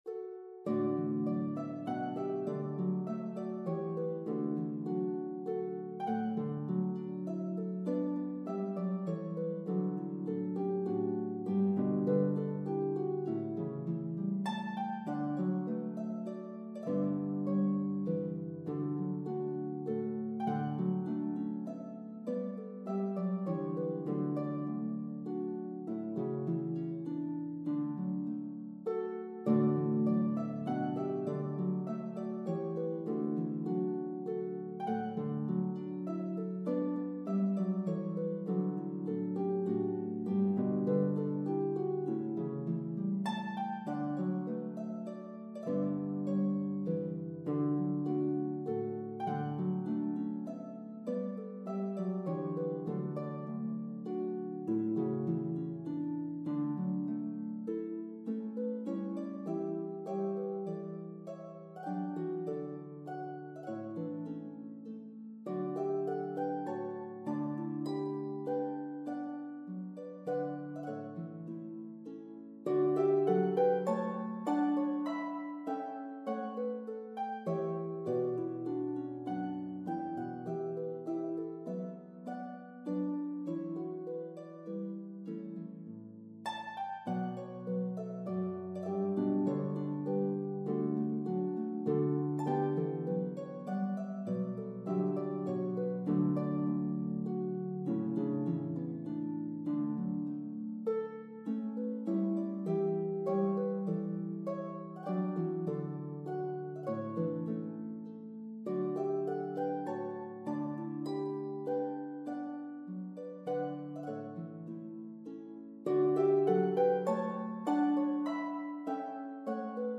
slow airs